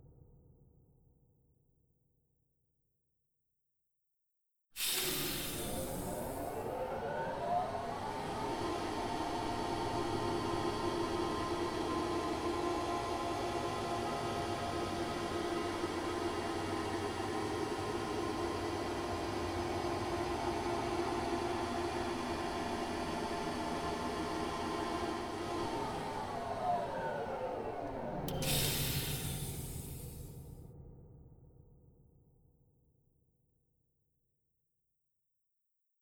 scifiElevatorDelay4sec.wav